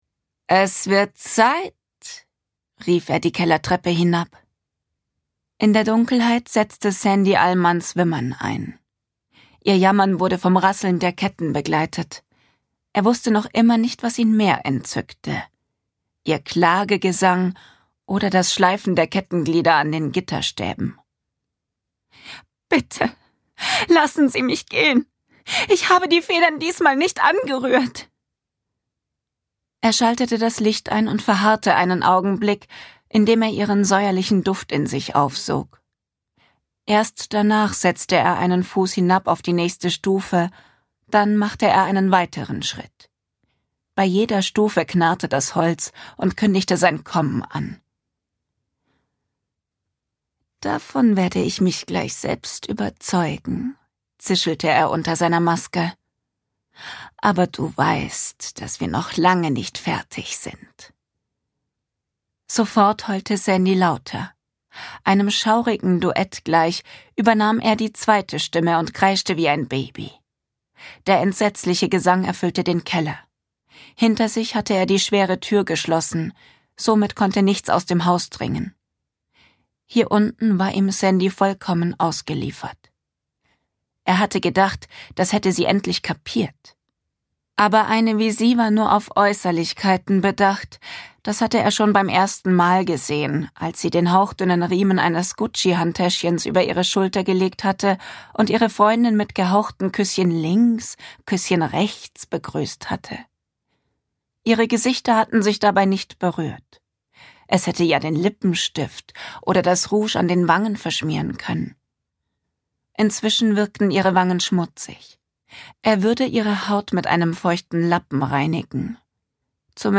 Wenn ich Hörbücher spreche